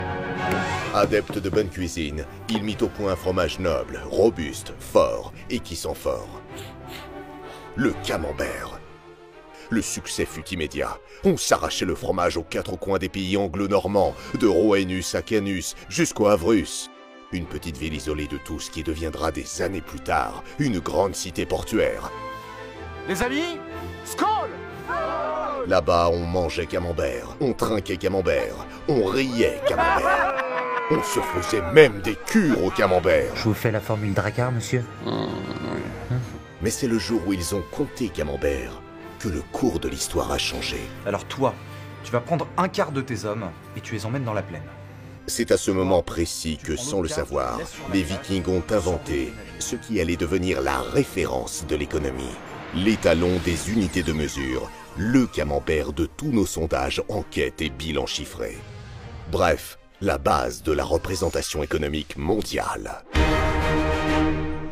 Prestation voix-off pour "Admissibles - Accroche toi bien" : ton décalé et viril
Voix conteur parodique.
Ma mission en tant que voix-off de la publicité « Admissibles – Accroche toi bien, ils reviennent » était de transmettre l’excitation et l’anticipation de l’évènement, tout en conservant un ton posé et décalé. J’ai donc utilisé une hauteur de voix très grave pour accentuer le côté viril et parodique de la campagne.